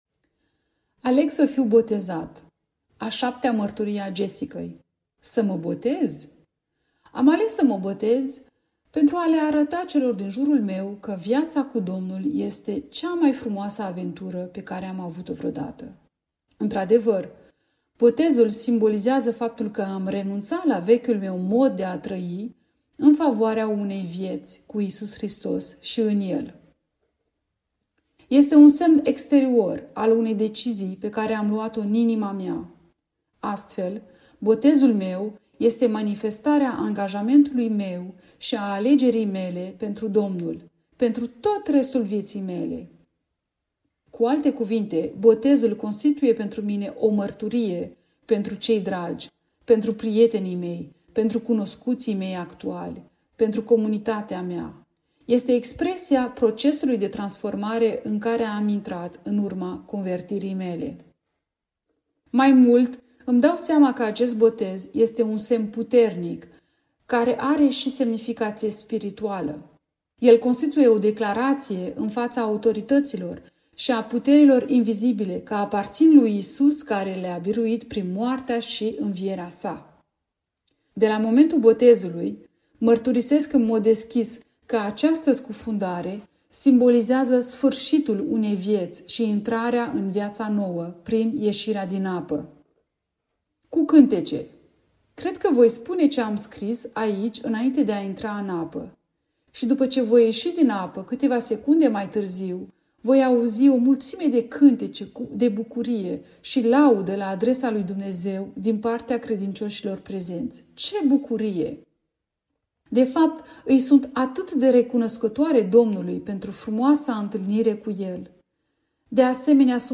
Aleg-sa-fiu-botezat-lent.mp3